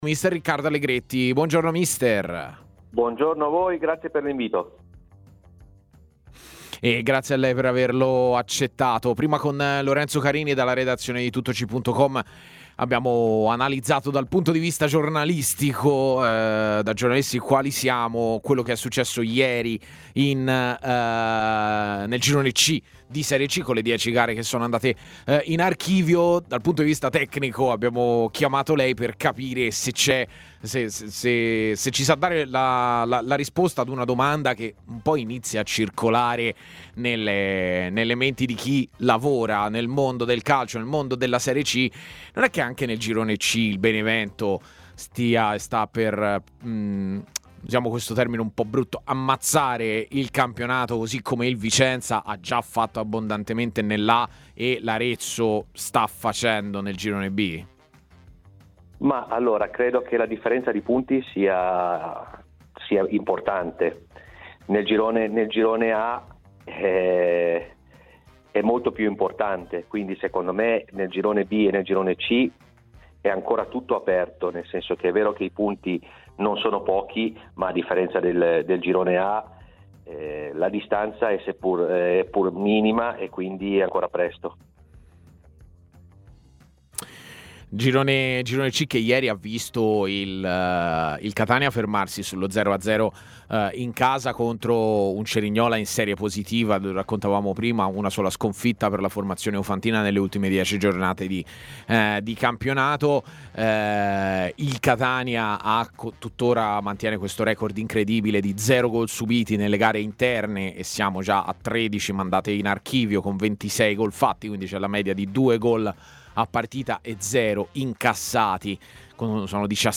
è intervenuto all'interno della trasmissione ' A Tutta C ' per fare il punto della situazione sul campionato di terza serie.